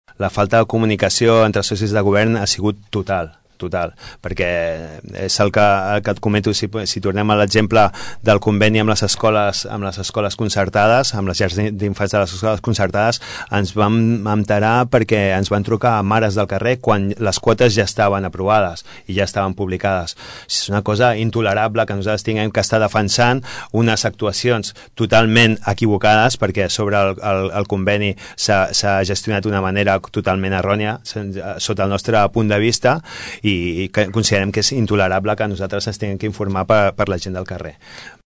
Així ho explicava aquesta tarda, el regidor i portaveu de Som Tordera, Salvador Giralt en una entrevista a Ràdio Tordera.